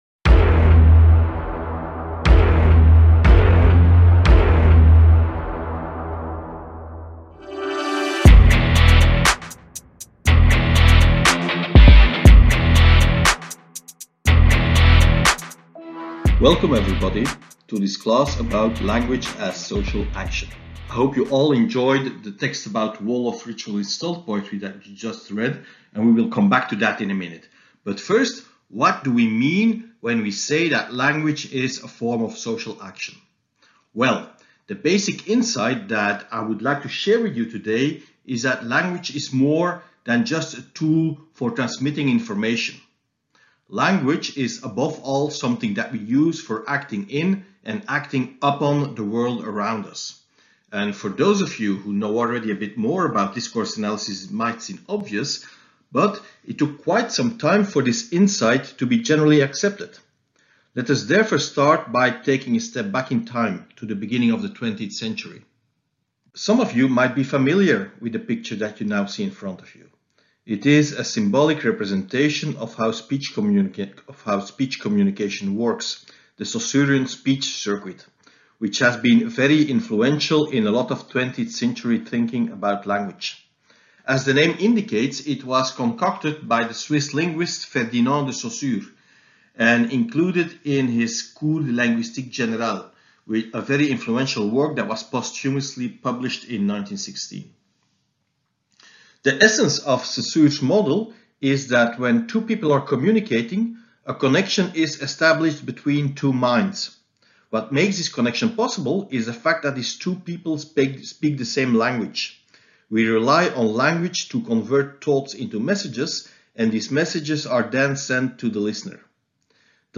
Language as Social Action - Lecture — Moniviestin